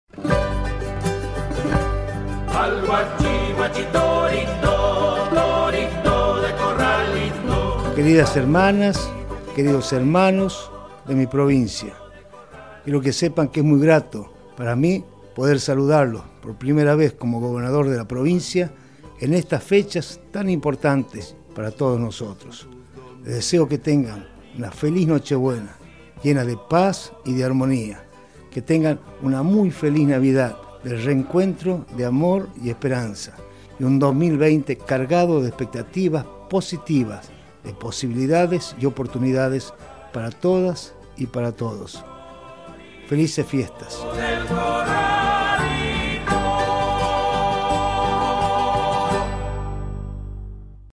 Destacando la Solidaridad y la esperanza, Ricardo Quintela destaca este primer saludo como máxima figura administrativa provincial.